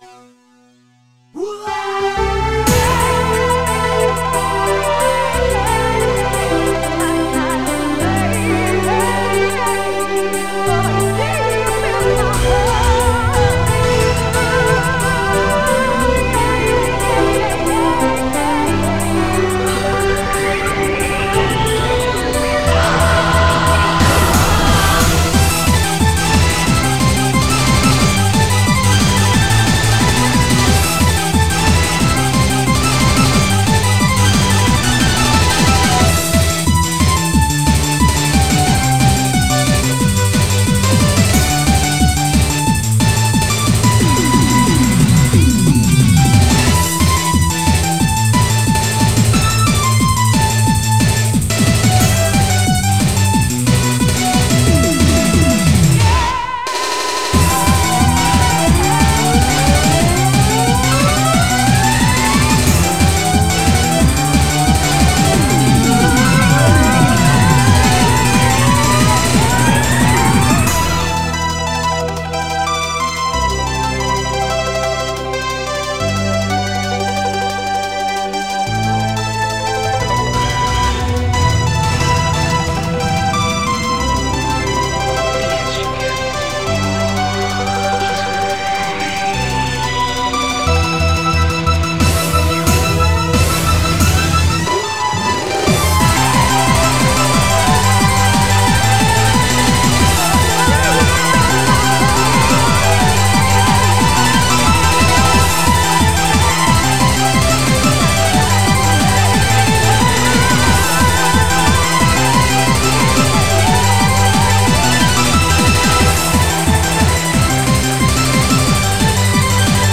BPM90-180
Audio QualityPerfect (Low Quality)